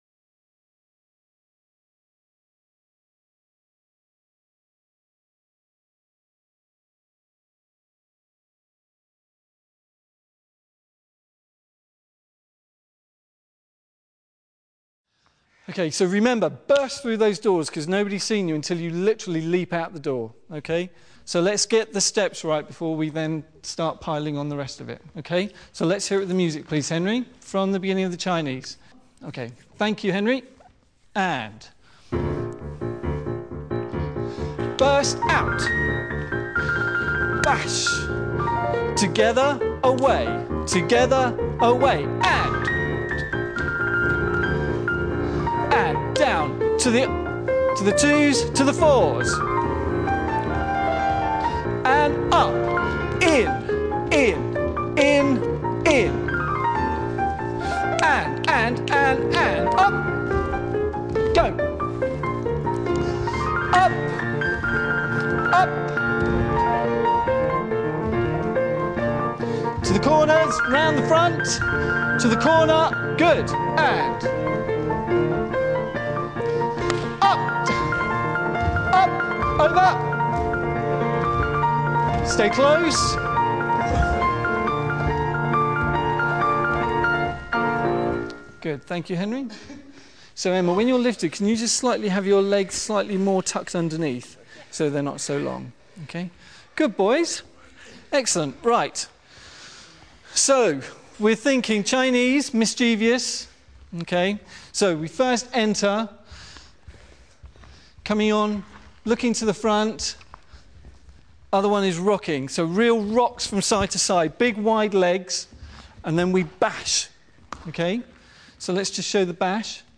ROH - The Nutcracker in Rehearsal